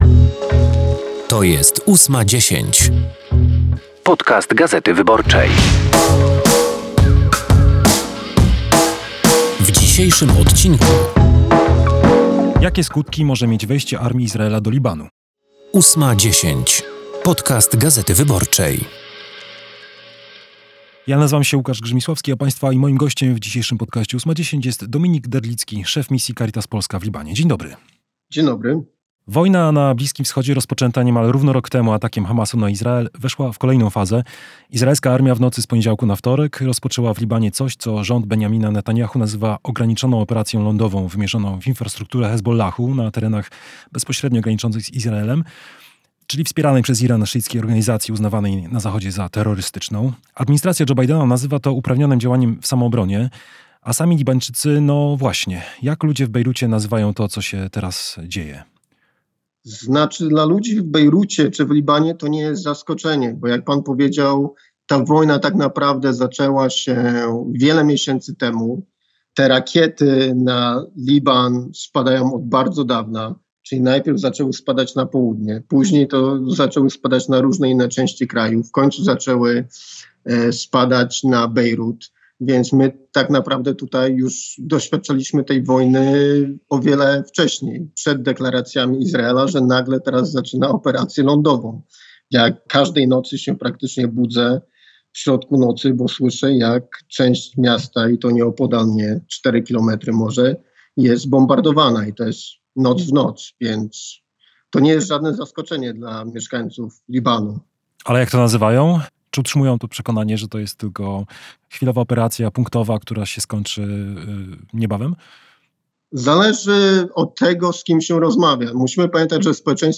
rozmawia z Cezarym Tomczykiem, wiceministrem obrony narodowej i wiceprzewodniczącym Platformy Obywatelskiej, o szczegółach akcji pomocowej "Feniks" na terenach popowodziowych. Jaka jest wartość zniszczeń spowodowanych przez powódź? Ilu żołnierzy jest zaangażowanych w akcję pomocową?